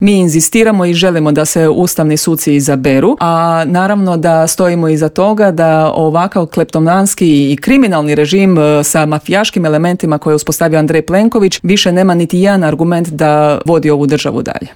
SDP-u je neprihvatljivo da se prvo glasa o opozivu premijera, a tek onda o ustavnim sucima, poručila je jutros u Intervjuu Media servisa zastupnica SDP-a Mirela Ahmetović.